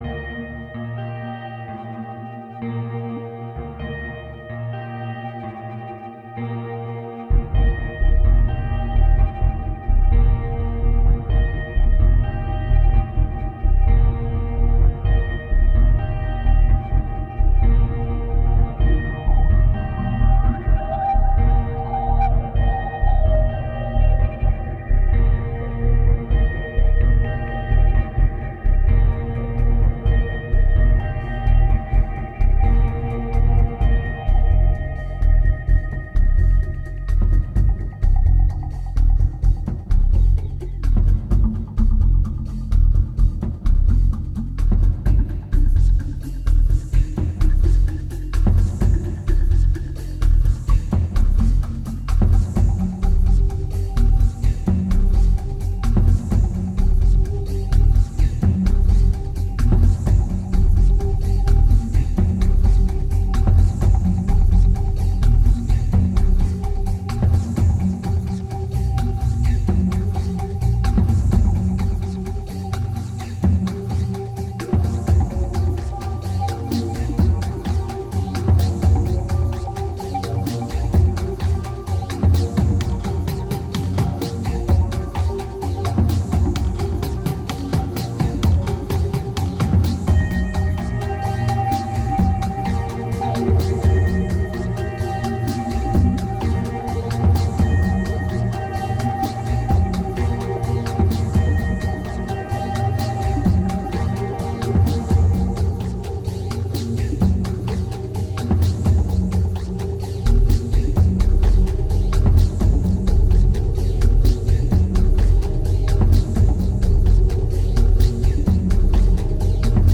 2254📈 - -24%🤔 - 128BPM🔊 - 2010-11-27📅 - -240🌟